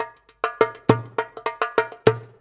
100DJEMB23.wav